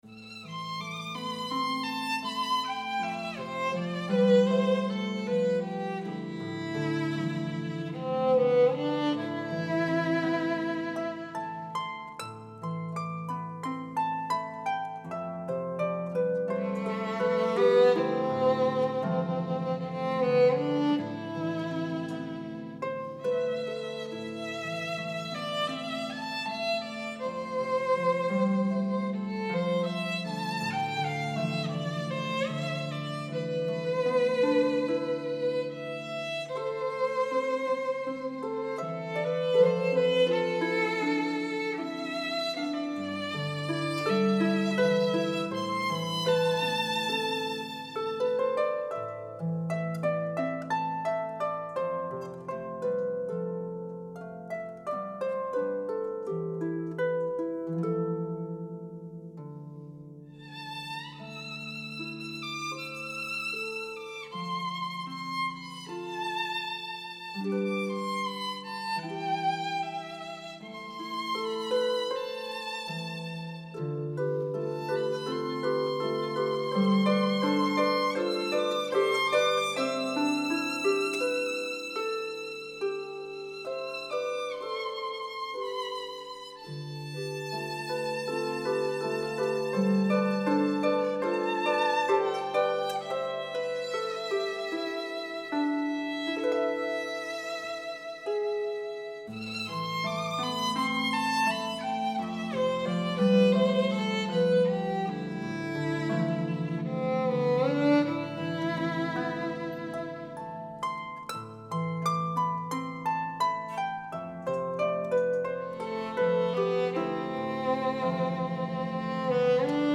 violin.